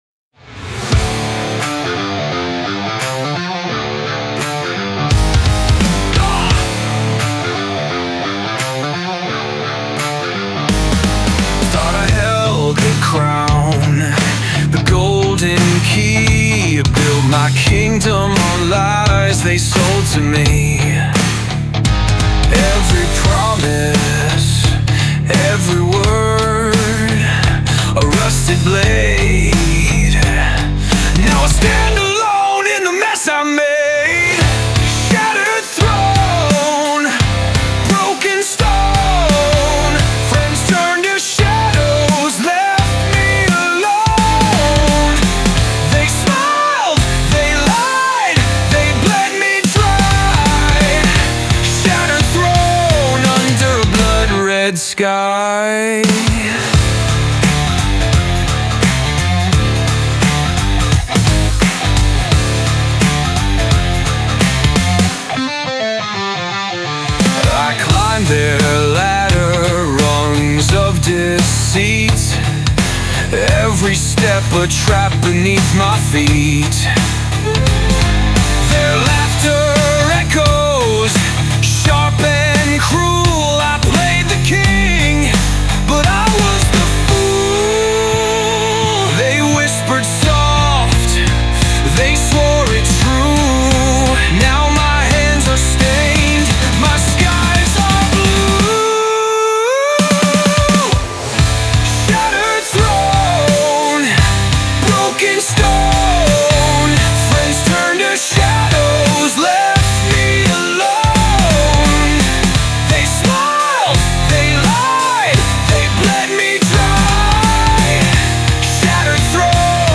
rock song